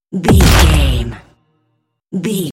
Dramatic double hit blood
Sound Effects
heavy
intense
aggressive
hits